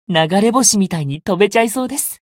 觉醒语音 流れ星みたいに飛べちゃいそうです 媒体文件:missionchara_voice_439.mp3